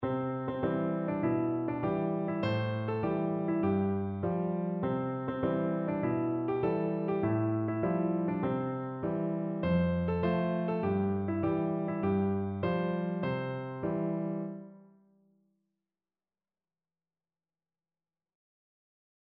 Piano version
No parts available for this pieces as it is for solo piano.
Playfully =c.100
2/4 (View more 2/4 Music)
Traditional (View more Traditional Piano Music)
world (View more world Piano Music)